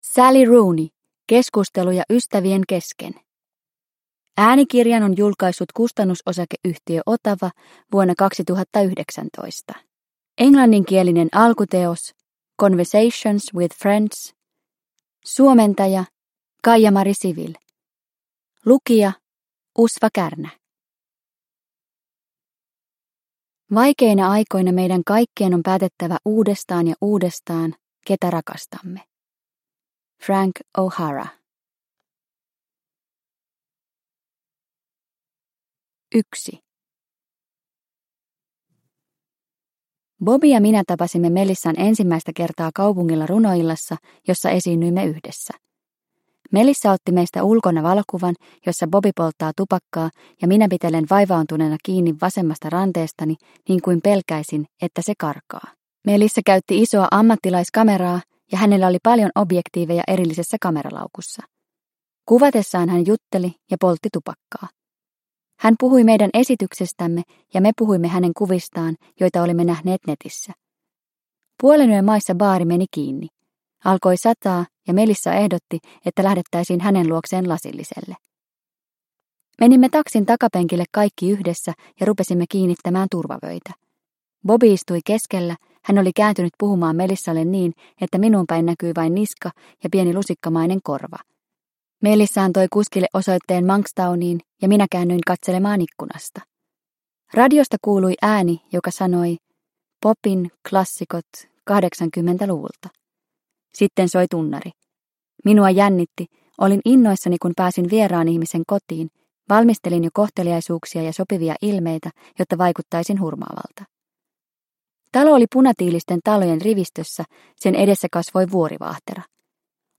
Keskusteluja ystävien kesken – Ljudbok – Laddas ner